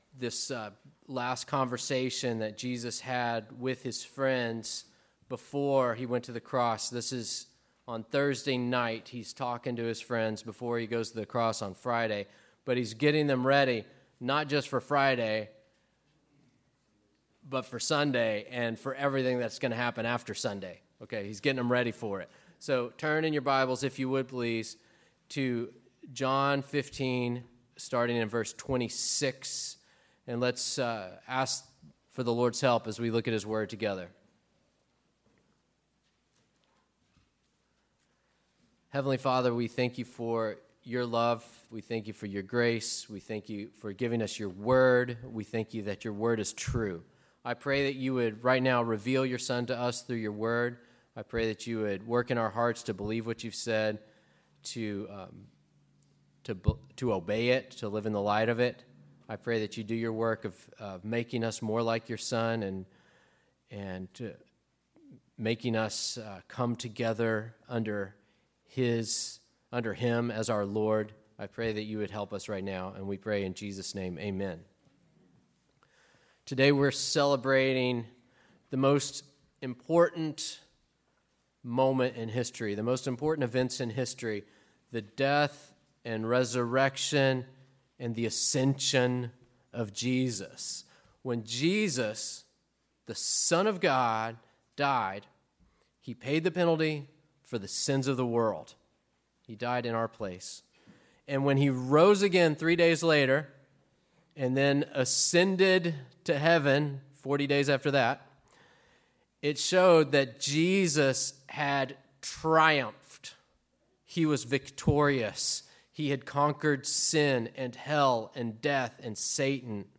Listen to sermons by our pastor on various topics.
Easter Service